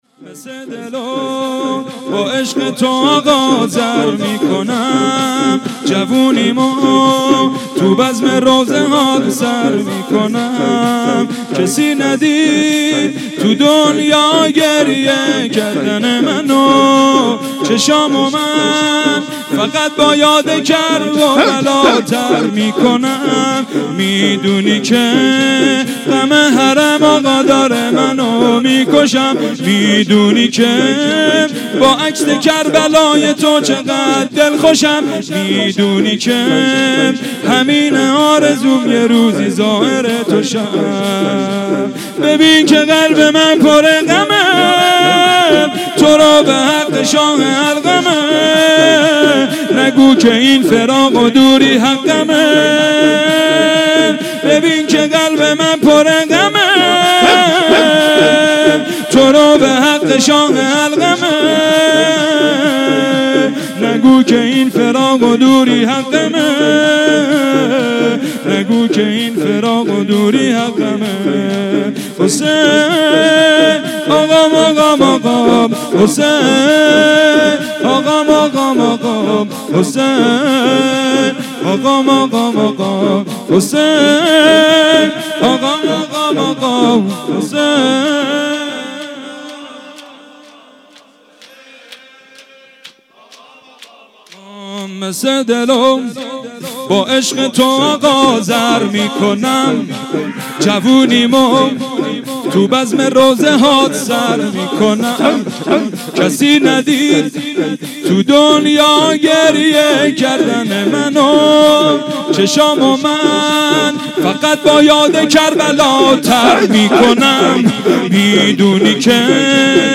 مداحی شب هفتم محرم